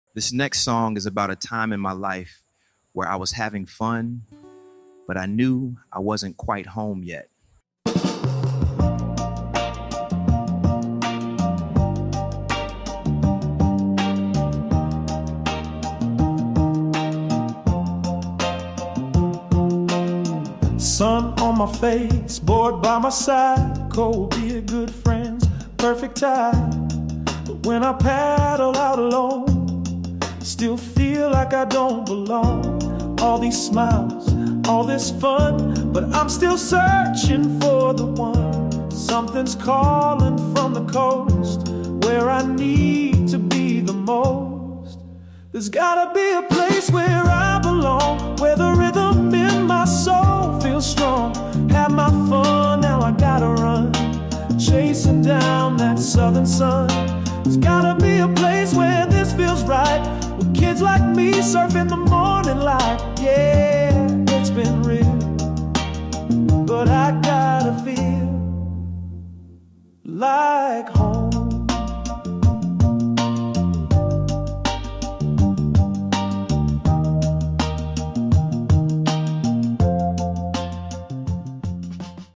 No voiceover, no intro I just dropped the piece.
I told it I wanted something soulful, with a surfer feel, and it helped shape the vibe. Once I had the lyrics right, I took them over to Suno and generated the music.